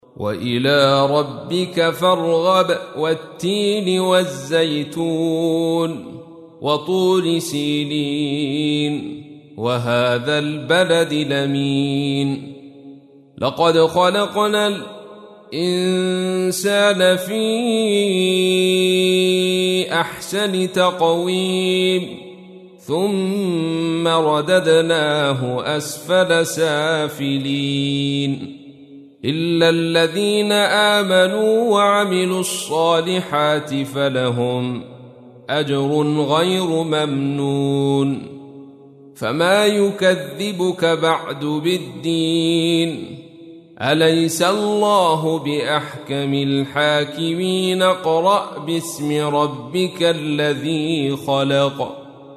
تحميل : 95. سورة التين / القارئ عبد الرشيد صوفي / القرآن الكريم / موقع يا حسين